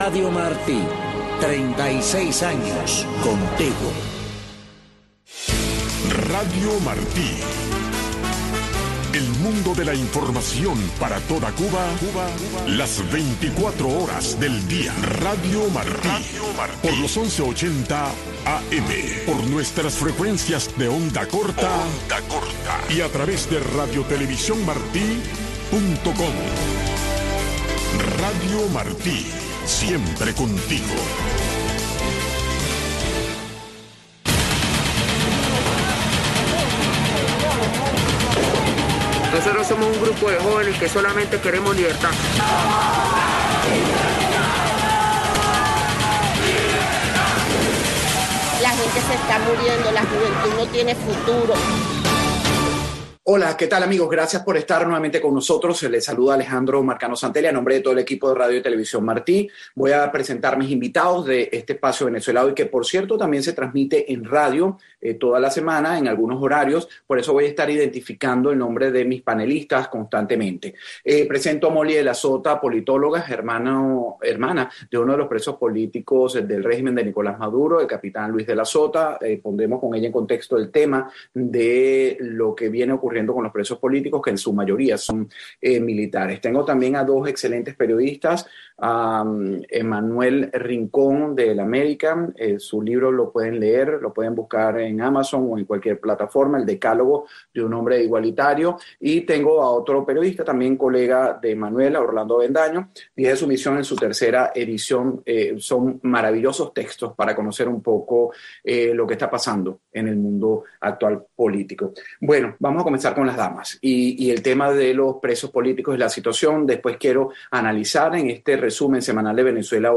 en el que se debaten las políticas actuales en Venezuela. Un espacio donde se respeta la libertad de expresión de los panelistas y estructurado para que el oyente llegue a su propia conclusión.